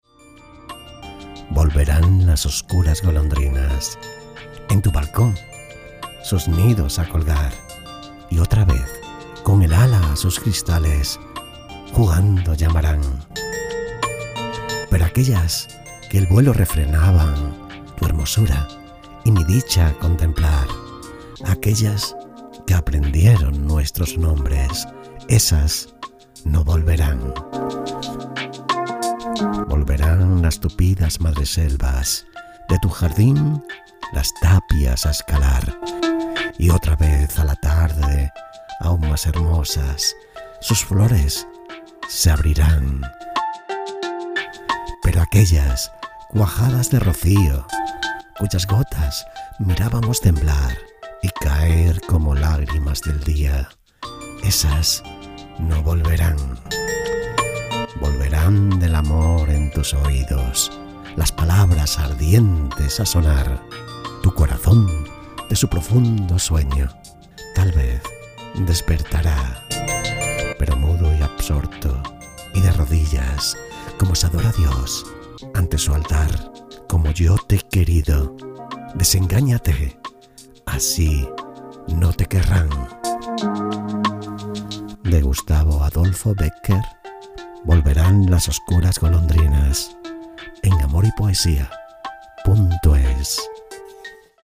Poesias de amor recitadas de Becquer
Poema de amor de Gustavo Adolfo Bécquer